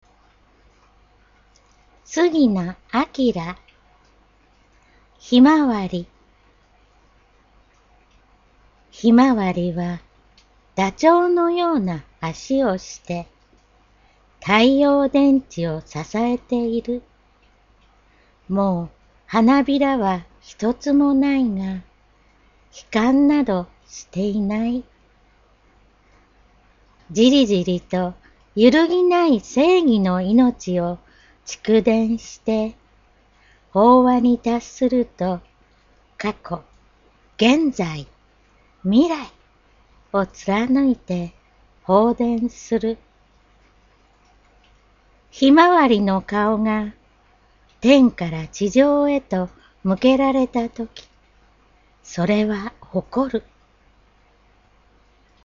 インターネットラジオ